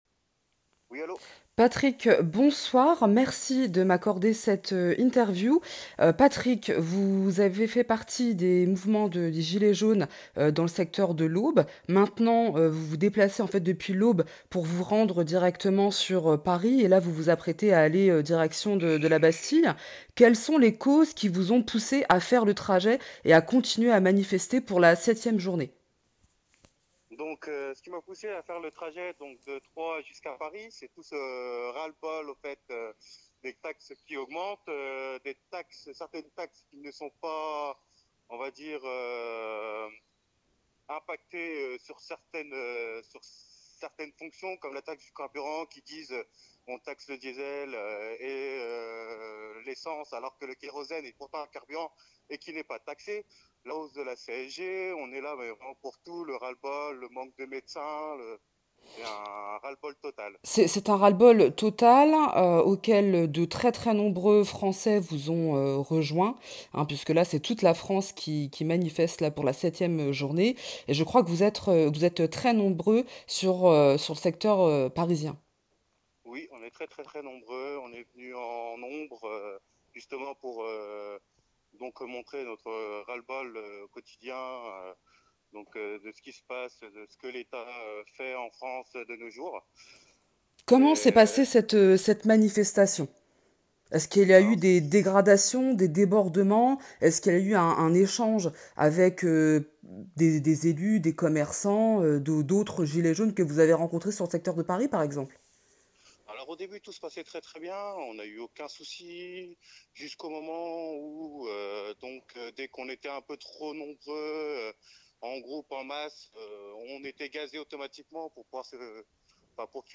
A la fin de notre interview téléphonique, il nous confie prendre la direction de la place de la Bastille, pour se faire entendre, à nouveau.